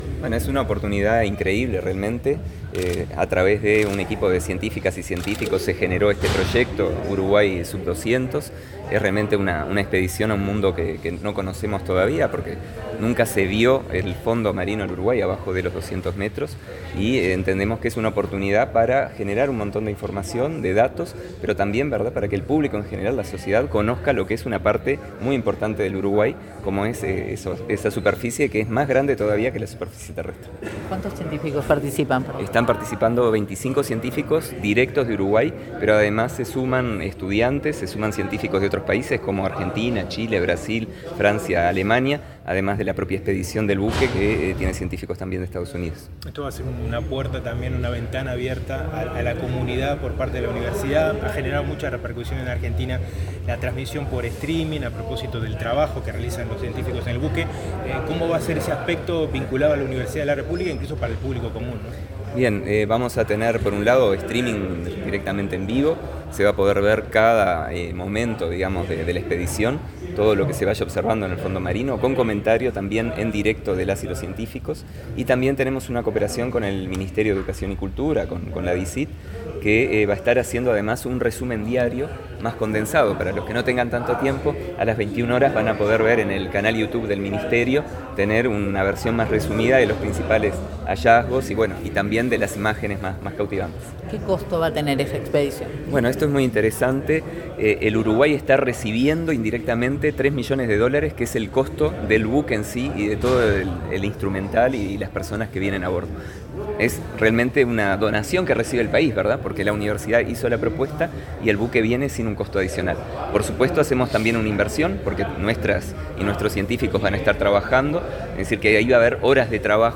Declaraciones del rector de la Universidad de la República, Héctor Cancela 19/08/2025 Compartir Facebook X Copiar enlace WhatsApp LinkedIn En el marco de una conferencia del programa Expedición Uruguay Sub-200, el rector de la Universidad de la República, Héctor Cancela, realizó declaraciones.